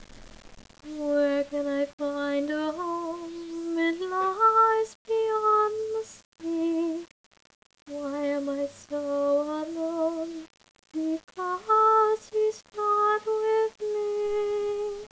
A little tune I'm working on :)
The only problem is that I can't make out all the words.